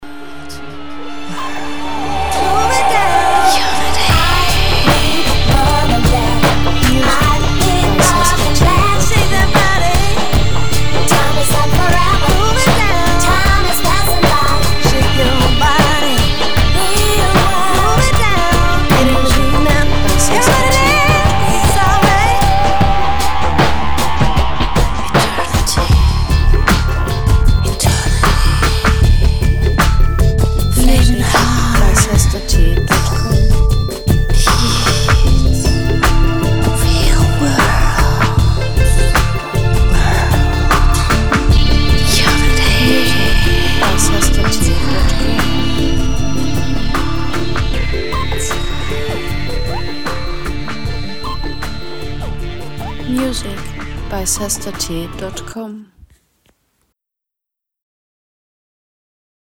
Single Version
• BPM 84
• Genre Pop/Triphop
Hip-Hop/Dark-Ambient-Song